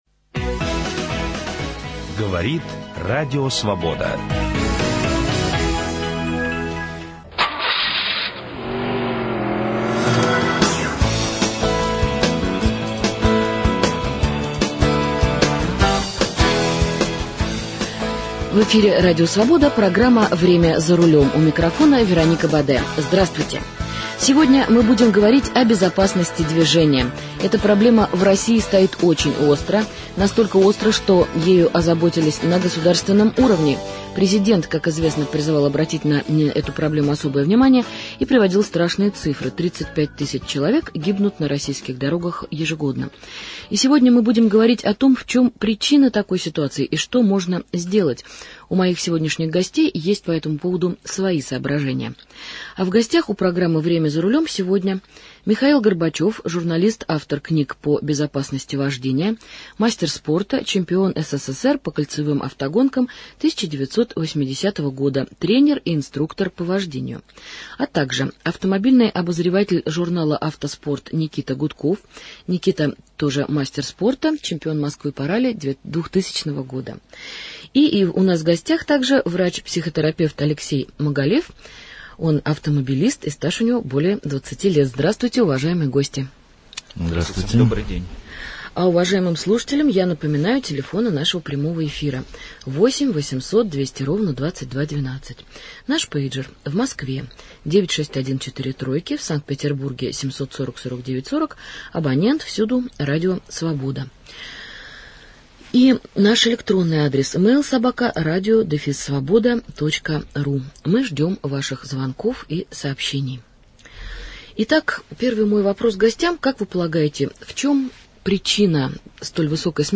Аудио интервью разное